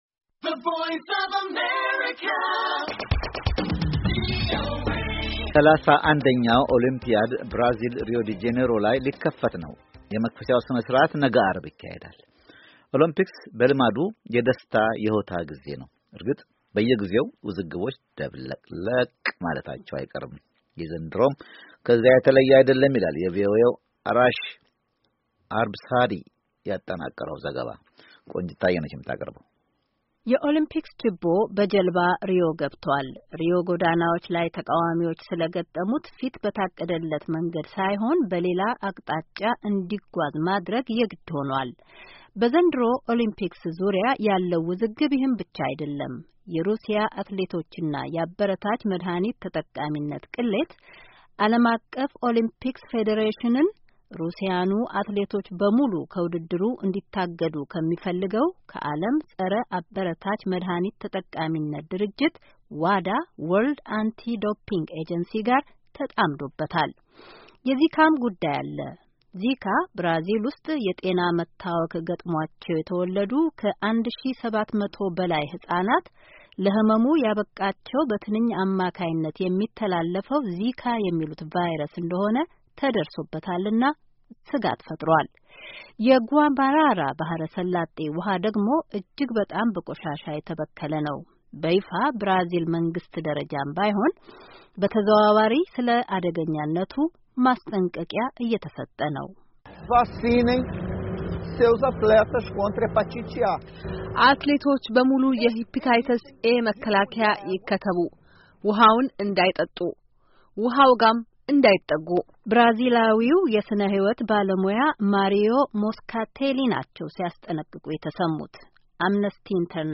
የሪዮ ኦሎምፒክ ዝግጅትና በውድድሩ ዙርያ የተከሰቱ ውዝግቦችን ያጠናከረ ዘገባ ከተያያዘው የድምፅ ፋይል ያድምጡ።